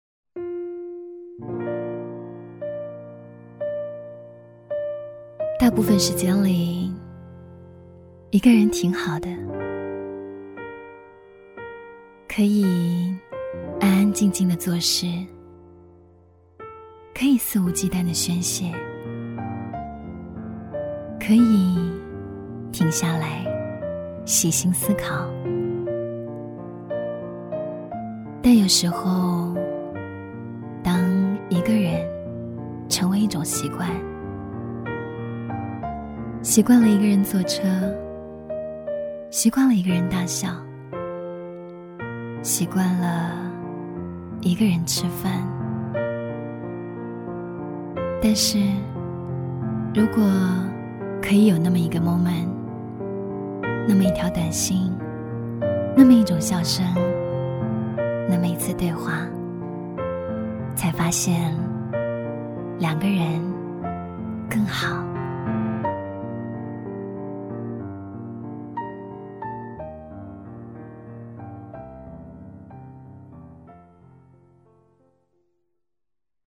• 女S155 国语 女声 微电影旁白-一个人-内心独白-清新、略带台湾腔 亲切甜美|感人煽情|素人